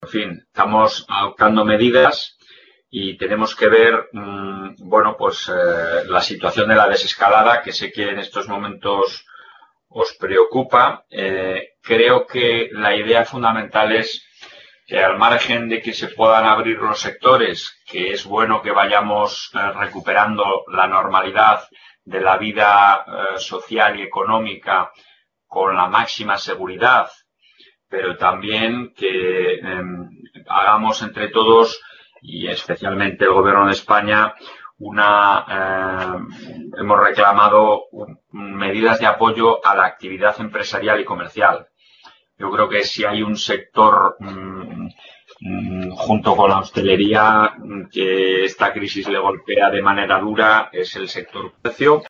Audio videoconferencia.